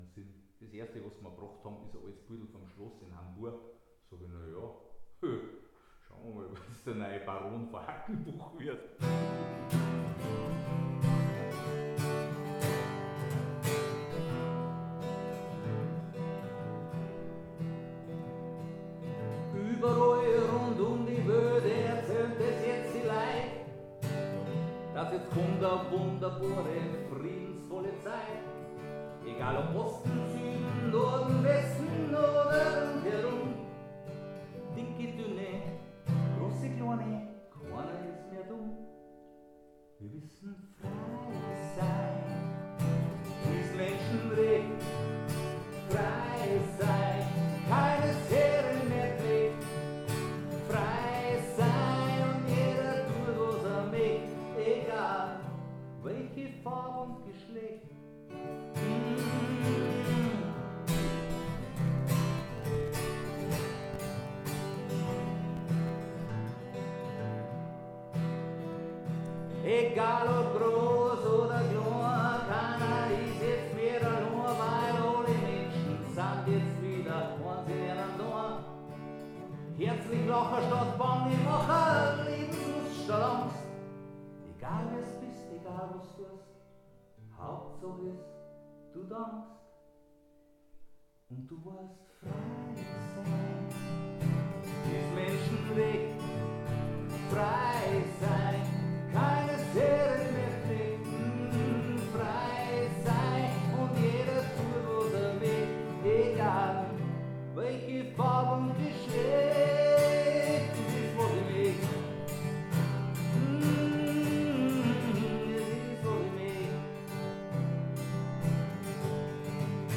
Hier noch ein paar Klangbeiträge vom gestrigen Mitschnitt 😀 ENJOY IT